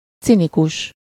Ääntäminen
Ääntäminen Tuntematon aksentti: IPA: /ˈt͡sinikuʃ/ IPA: /ʦi.ni.kus/ Haettu sana löytyi näillä lähdekielillä: unkari Käännös Ääninäyte Adjektiivit 1. cynical US Luokat Adjektiivit Latinasta johdetut sanat